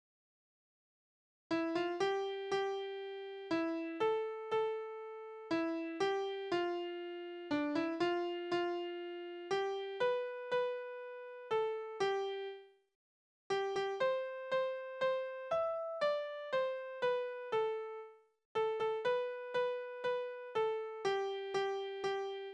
Soldatenlieder: Heldentod
Tonart: C-Dur
Taktart: C (4/4)
Tonumfang: große None
Besetzung: vokal